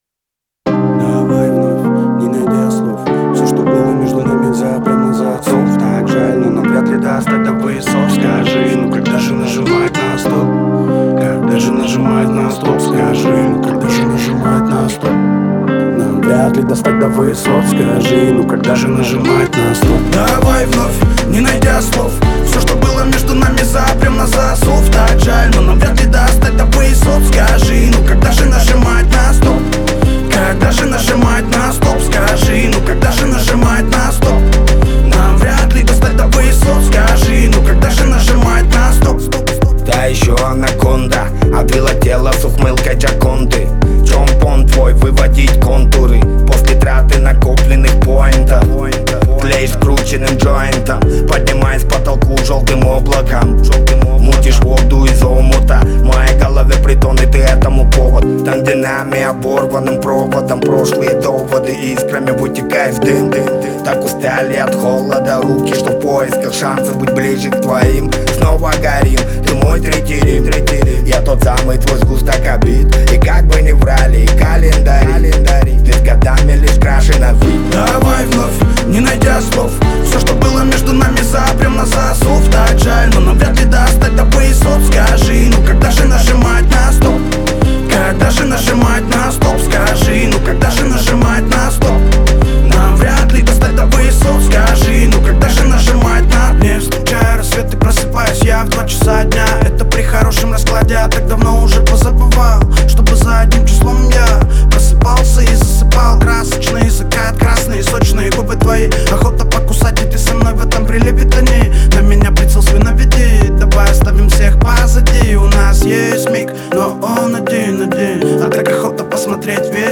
это трек в жанре хип-хоп